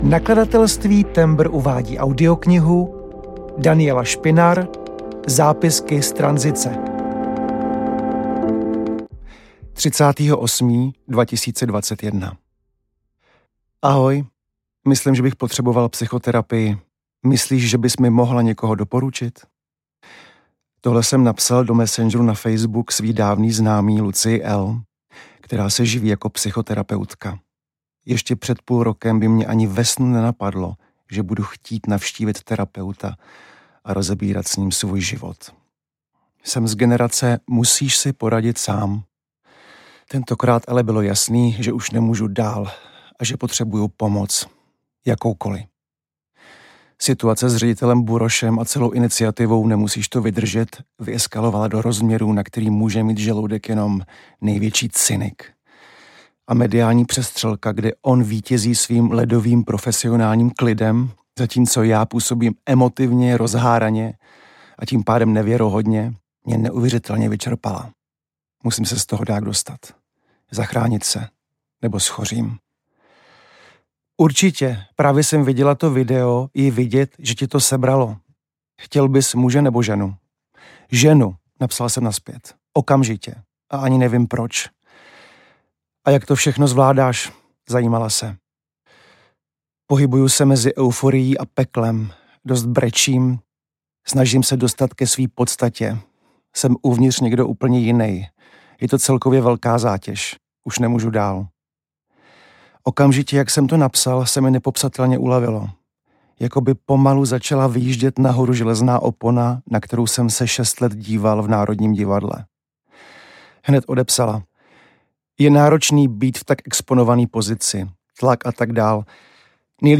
Zápisky z tranzice audiokniha
Ukázka z knihy
• InterpretDaniela Špinar